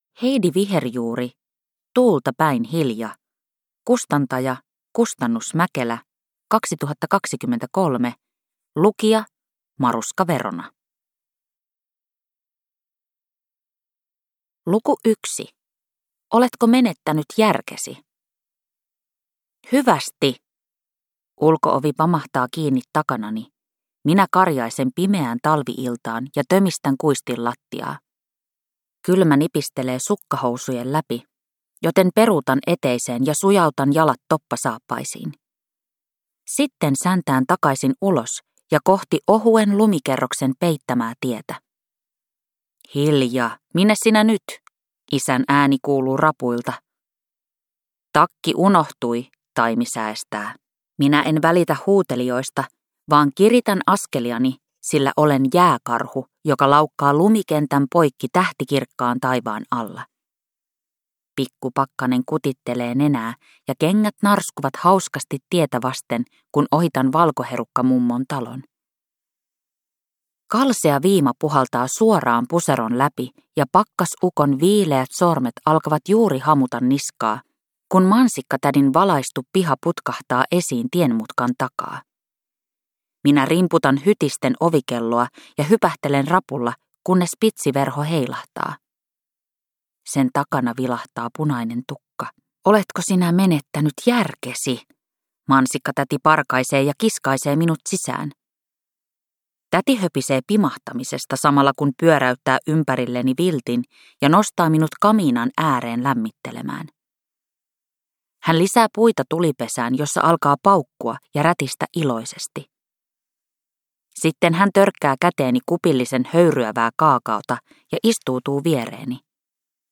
Kuunneltavissa myös äänikirjana useissa eri äänikirjapalveluissa